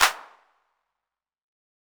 80-s-clap.wav